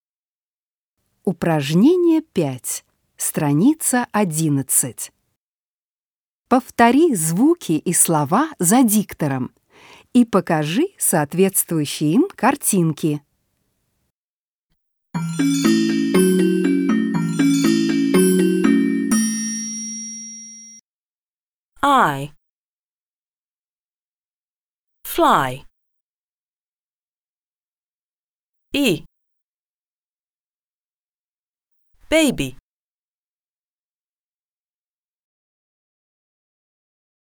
5. Повтори звуки и слова зам диктором и покажи соответствующие картинки.